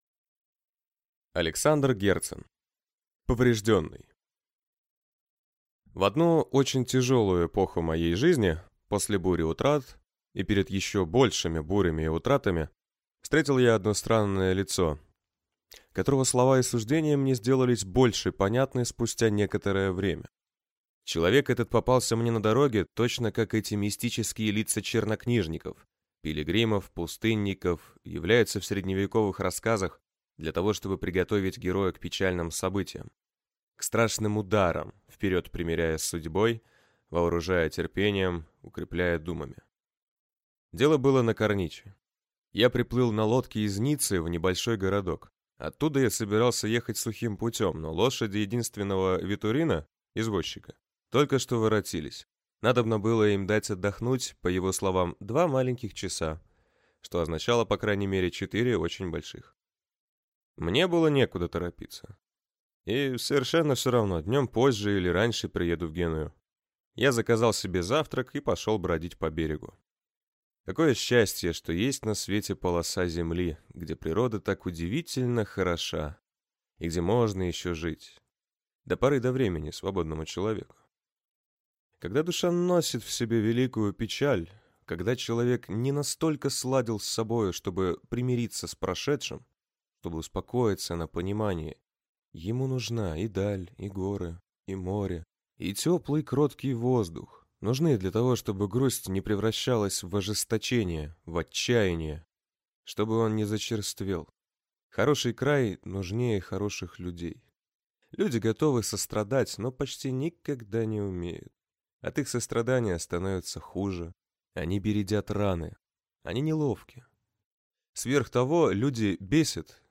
Аудиокнига Поврежденный | Библиотека аудиокниг